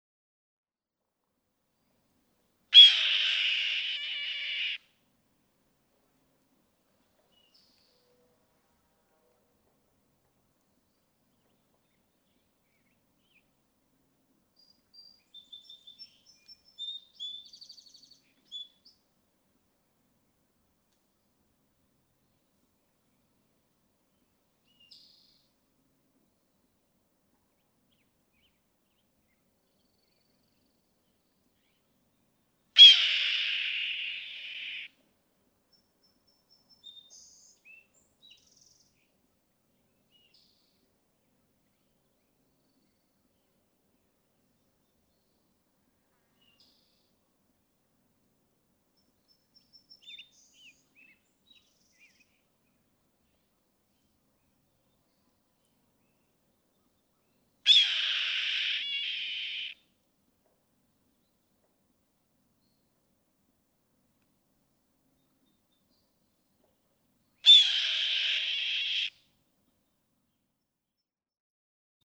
Red-tailed hawk
The trademark two- to three-second asthmatic kee-eeee-arrr, as heard in movies everywhere.
William L. Finley National Wildlife Refuge, Corvallis, Oregon.
539_Red-tailed_Hawk.mp3